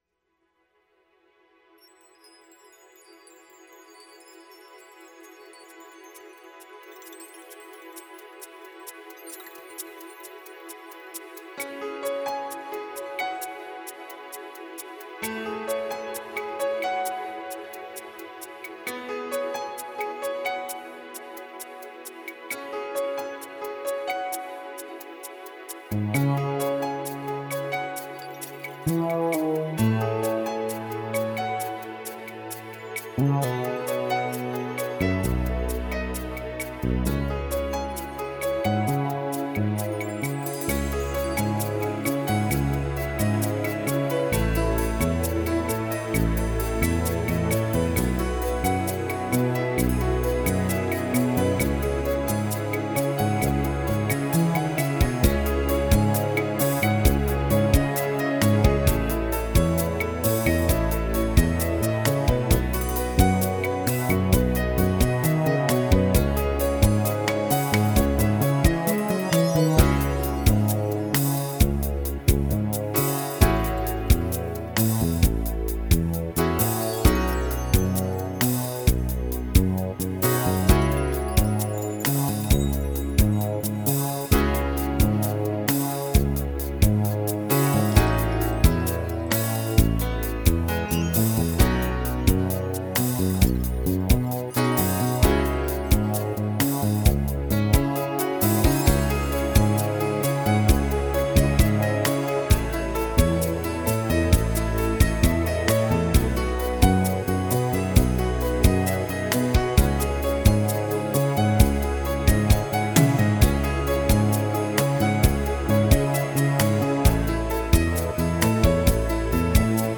in his makeshift home studio
These five pieces are all piano or keyboard based, and span over 5 years of creative effort.